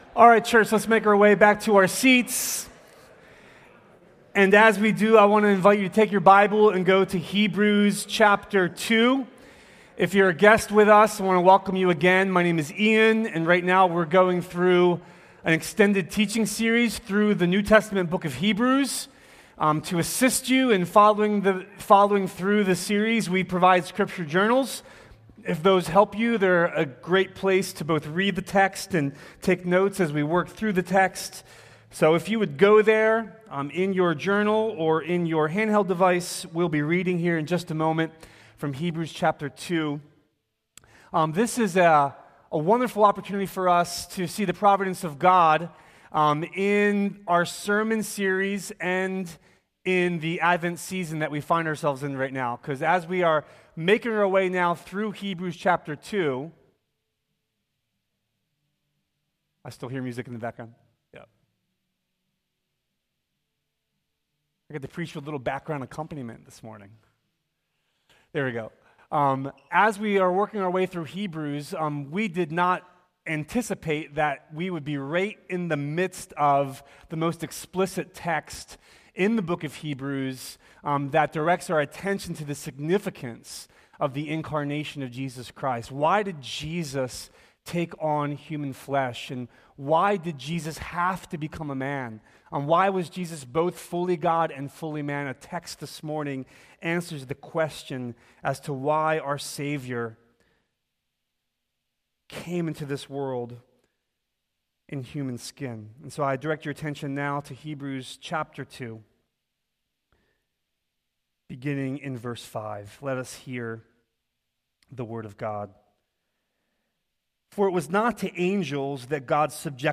A sermon from Hebrews 11:11–12 in the "Jesus is Better" series.